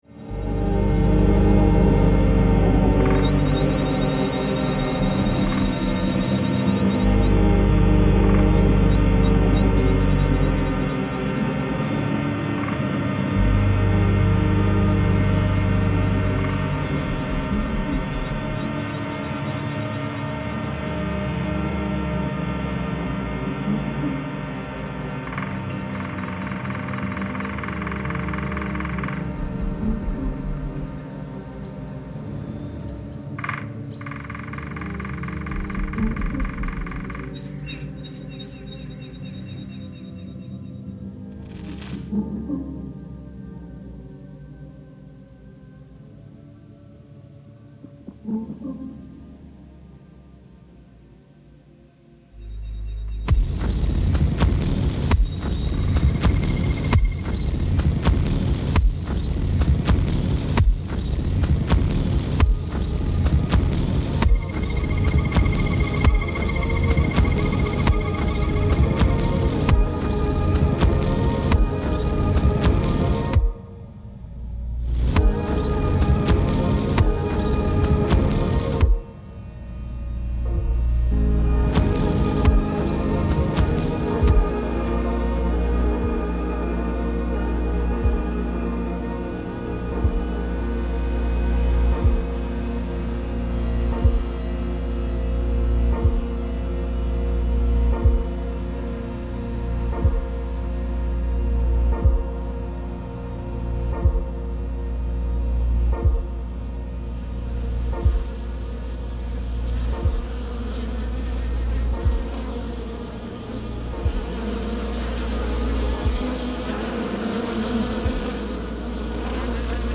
Multi-Channel-Soundinstallation
stereo version of the multichannel sound installation
Sound Art